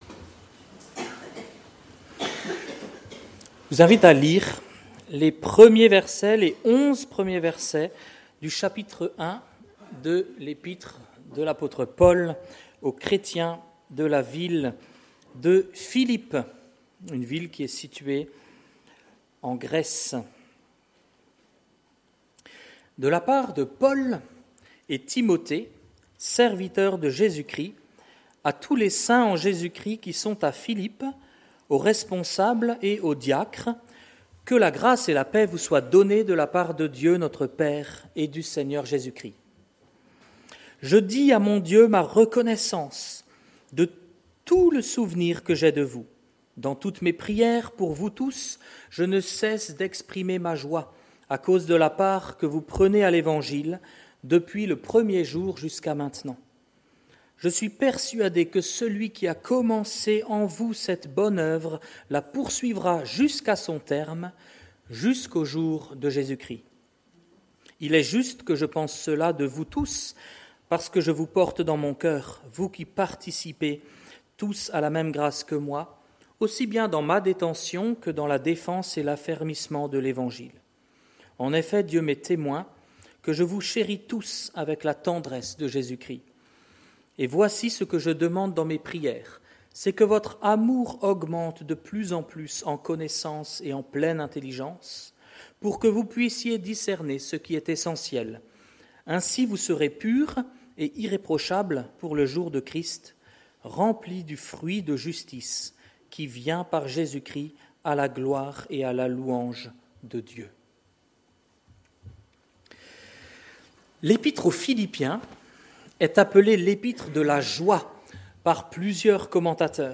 Culte du dimanche 8 septembre 2024 – Église de La Bonne Nouvelle